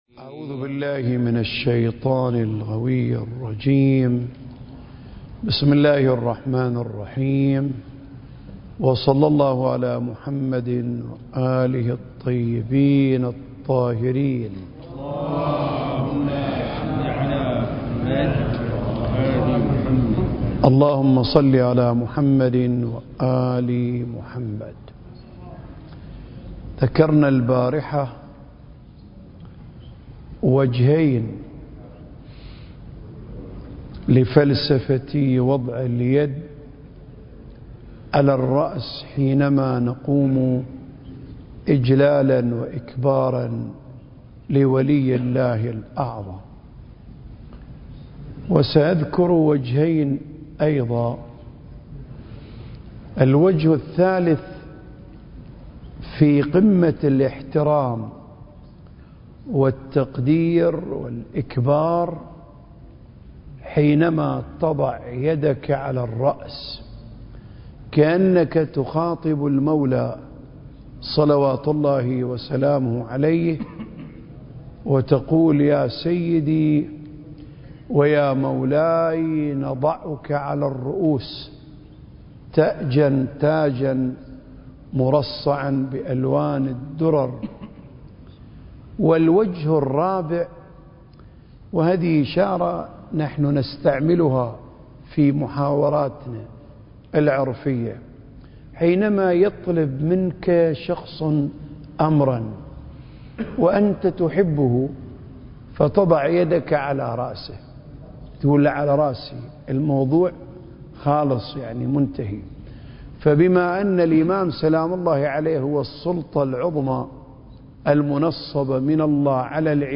سلسلة محاضرات: آفاق المعرفة المهدوية (7) المكان: الأوقاف الجعفرية بالشارقة التاريخ: 2023